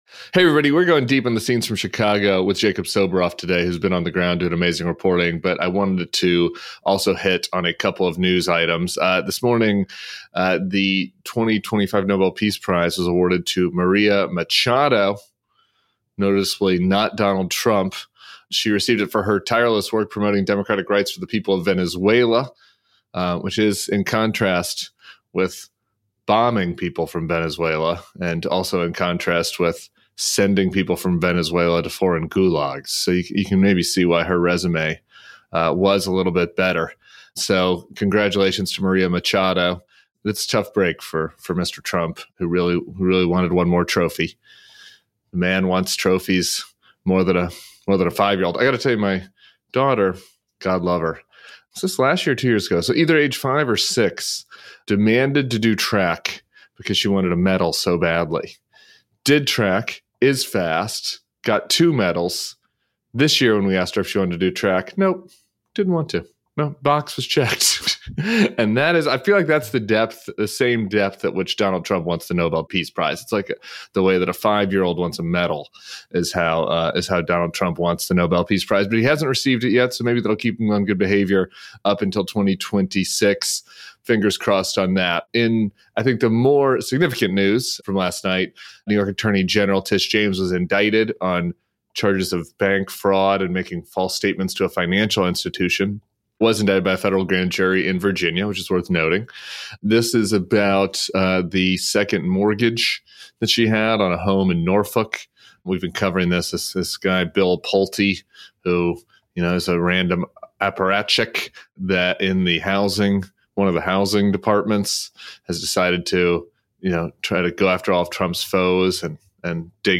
Plus, from our live show in D.C., Rep. Sarah McBride talks with Sarah Longwell about the need for a big tent coalition without purity tests, and how the Dem demands for a renewal of the ACA subsidies is also a challenge to Trump’s authoritarian power grab. Jacob Soboroff joins Tim Miller and Rep. Sarah McBride was live with Sarah Longwell.